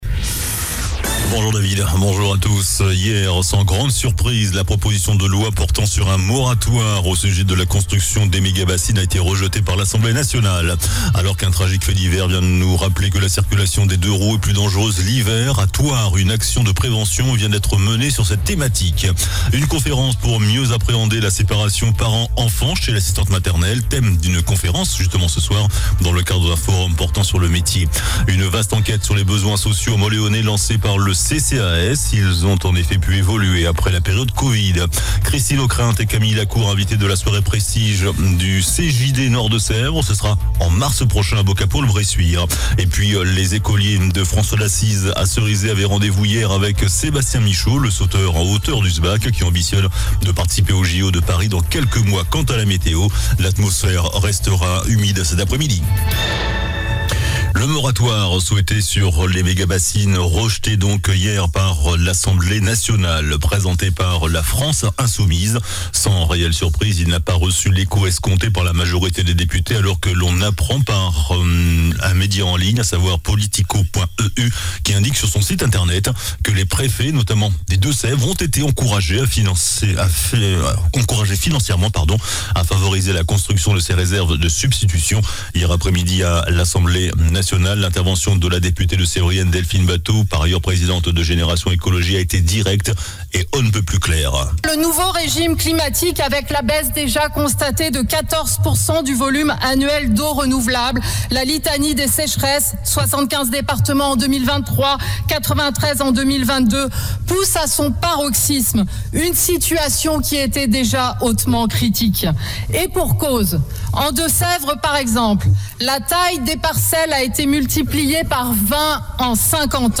JOURNAL DU VENDREDI 01 DECEMBRE ( MIDI )